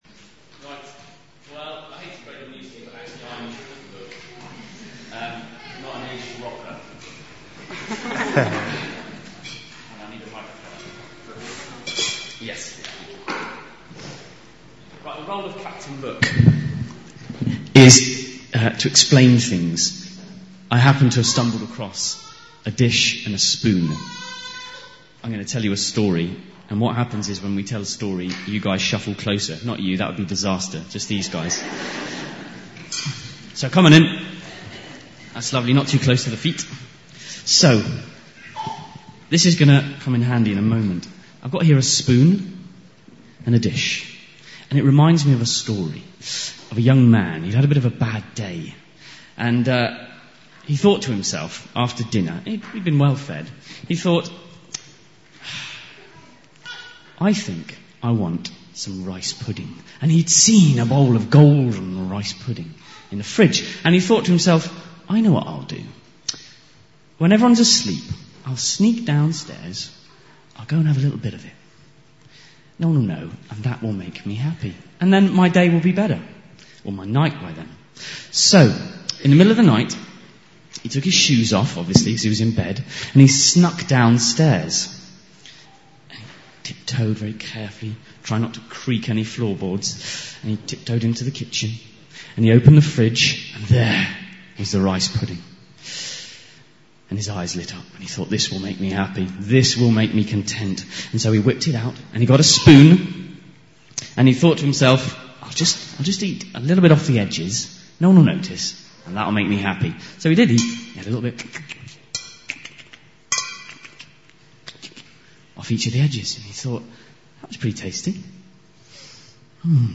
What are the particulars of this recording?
Conclusion after the 2008 Holiday Club Led by: